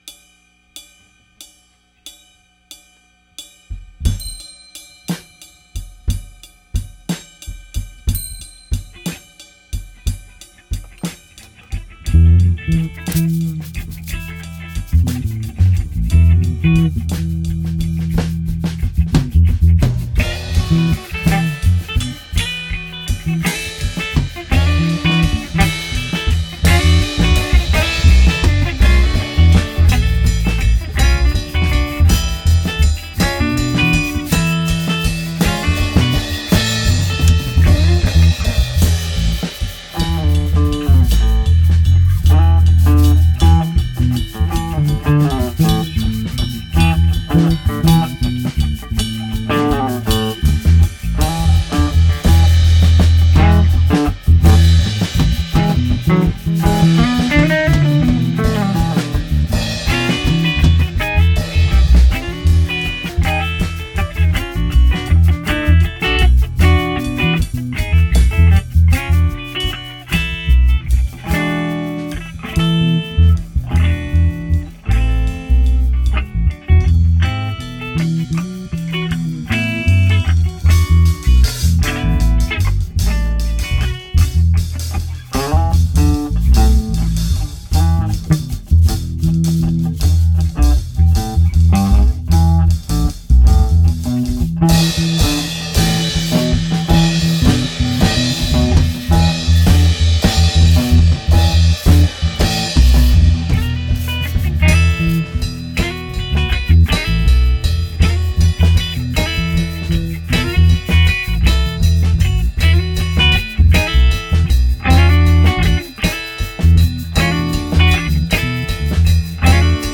bass
left-hand Ludwig drums